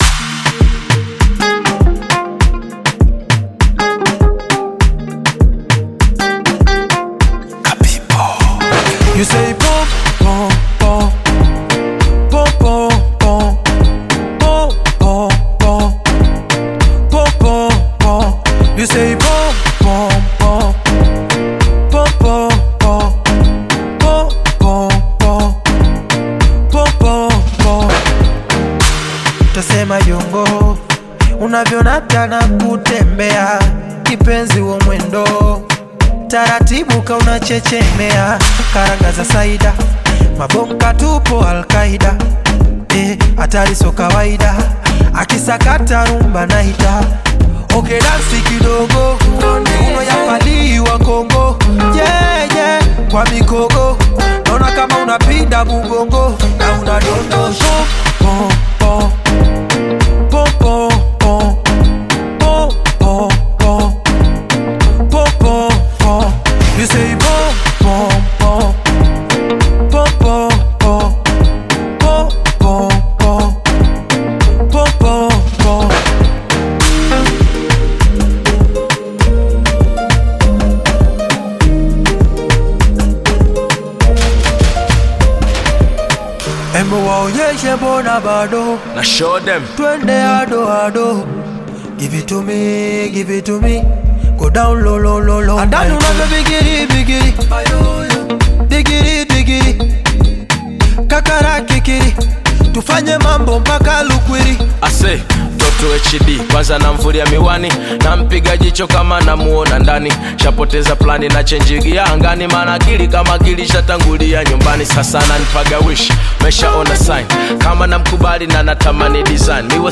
African Pop